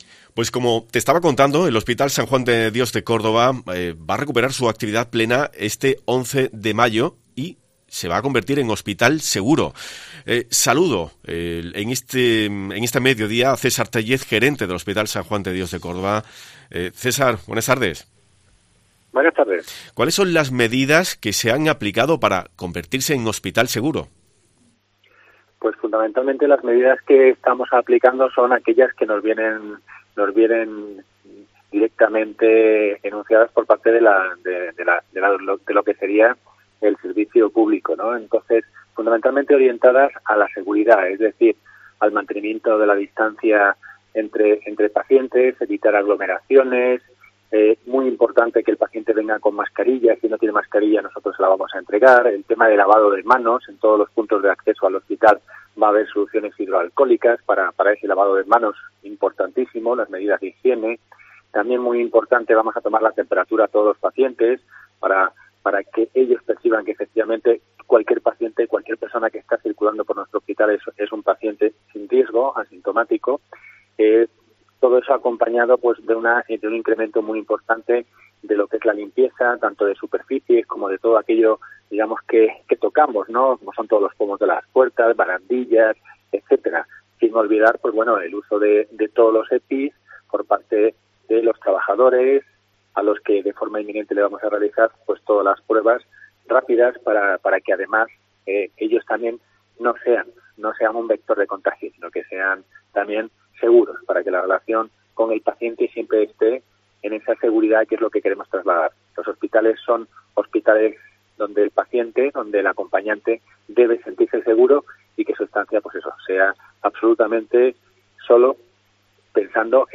responde a las preguntas